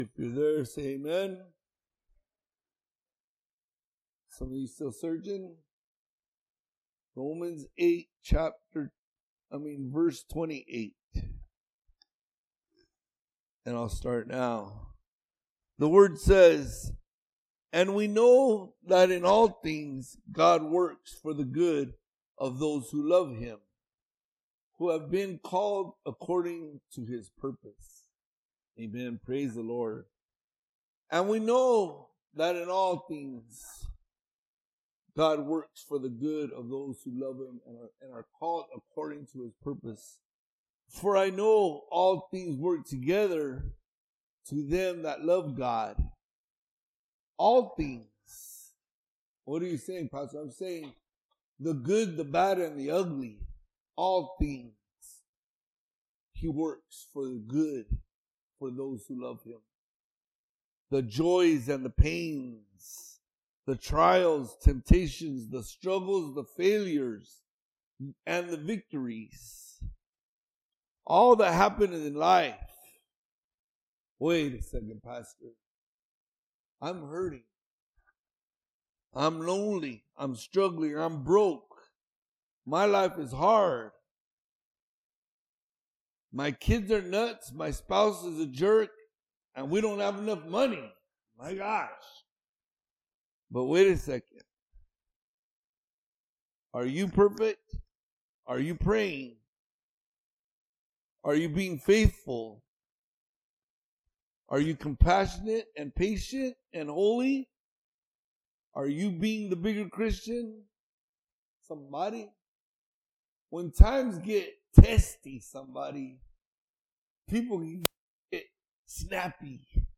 Podcast (piru-community-church-sermons): Play in new window | Download